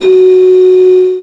55bw-flt15-fs4.aif